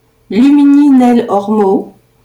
pronunciation file